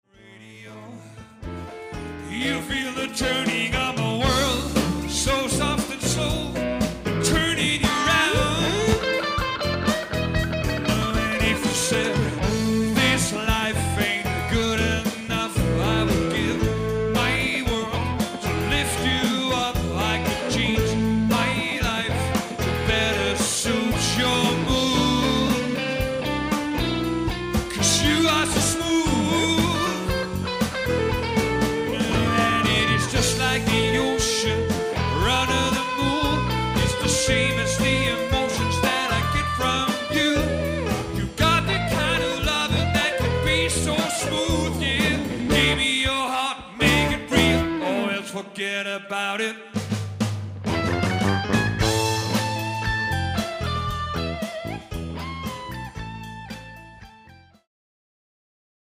solid cover musik til fester af enhver slags
dansevenlig rock, soul og funk musik
• Coverband
• Rockband